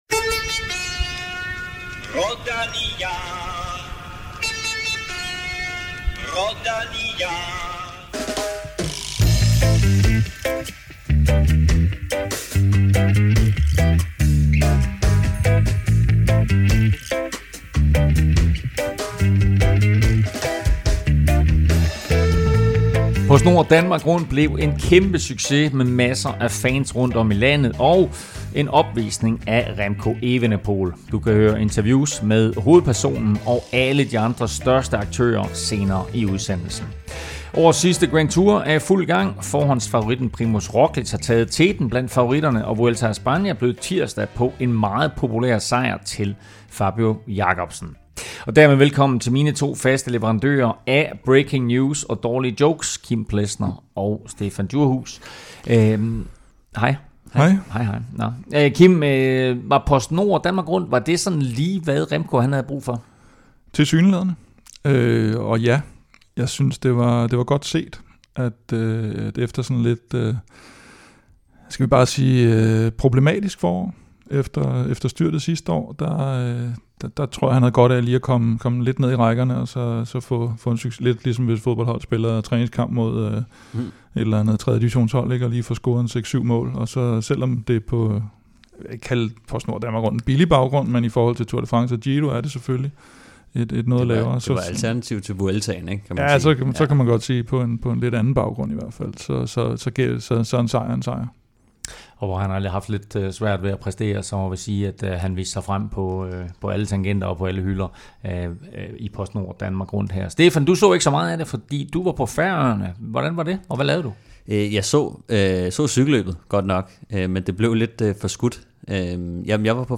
interviewe de største profiler i PostNord Danmark Rundt. Du kan høre fra Remco, Cavendish, Mads P og mange flere.